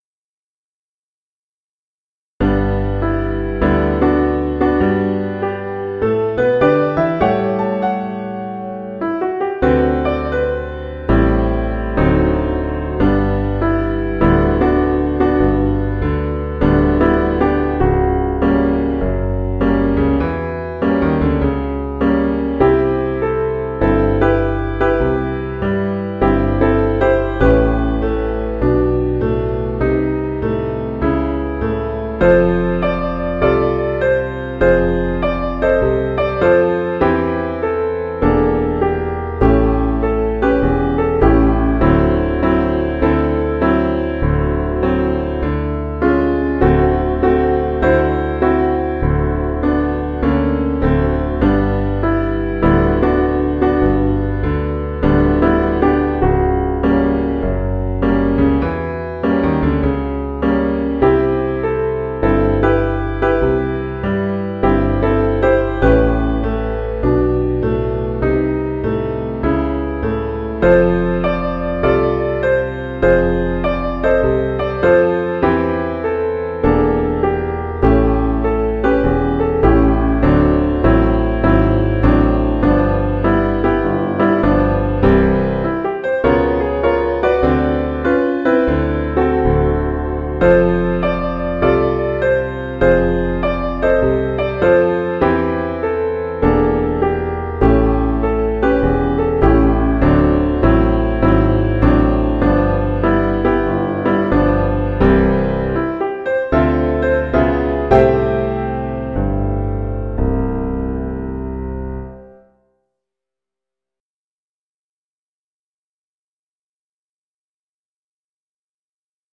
Music for Download VA = Virtual Accompaniment UNFORGETTABLE Sheet Music - Vocals unforgettable_VOICE.pdf VIRTUAL ACCOMPANIMENT unforgettable_VA.mp3 rehearsal recording rehearsal recording rehearsal recording YouTube